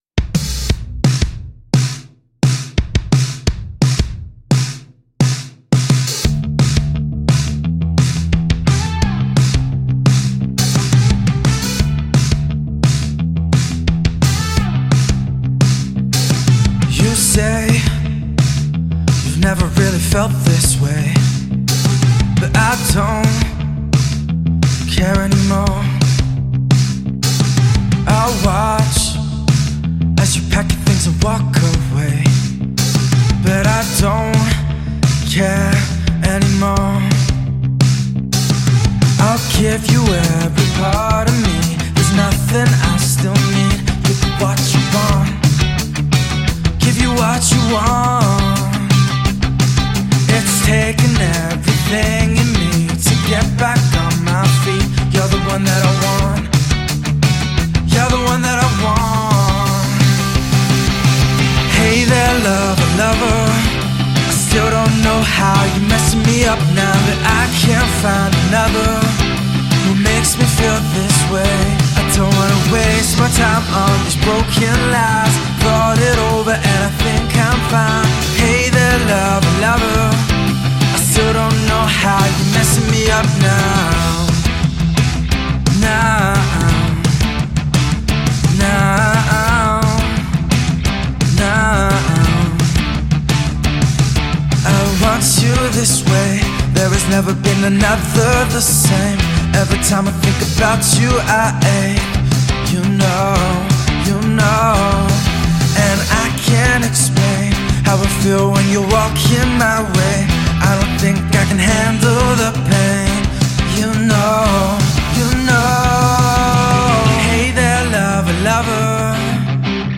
Punk Prog Drums Recording Rock